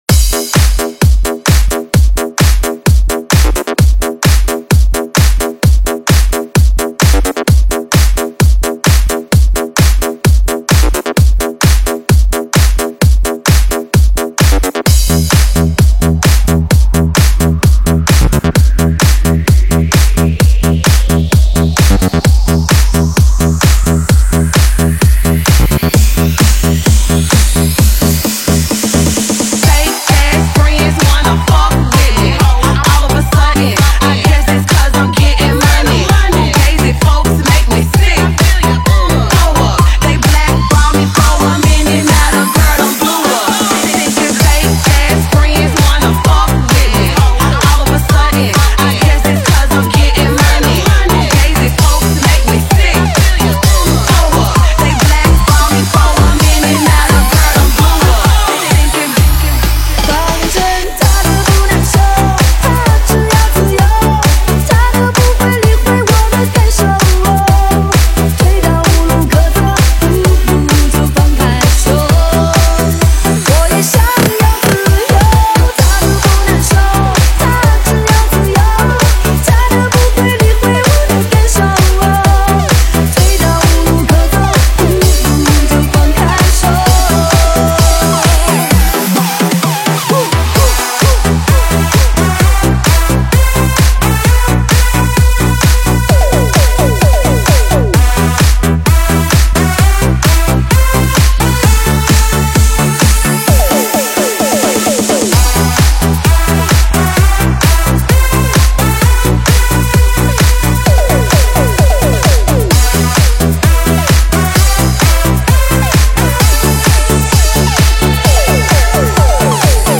中文 ProgHouse